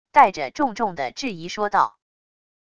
带着重重的质疑说道wav音频